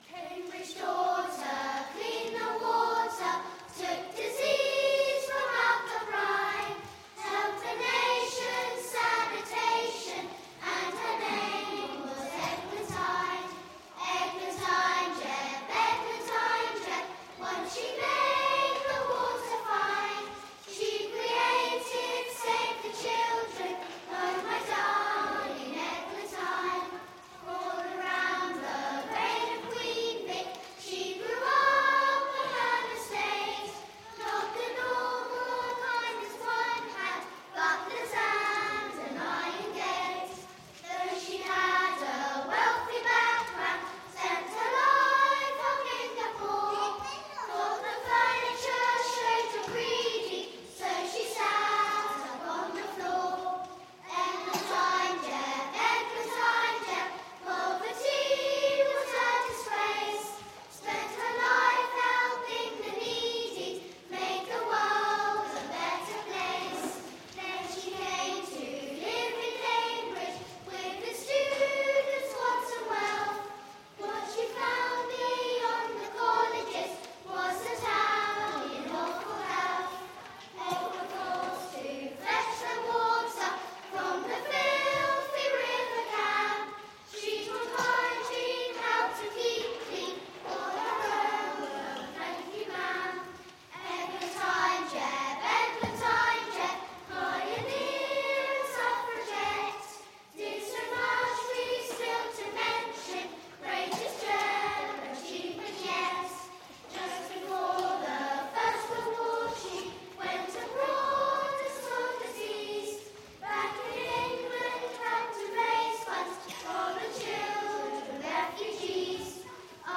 Eglantyne Jebb lyrics to celebrate the story of her life and achievements and her work in Cambridge and internationally have been composed by CBBC's Horrible Histories chief songwriter, Dave Cohen. Performed by Milton CofE Primary School ChoirTo the tune of 'Oh My Darling Clem...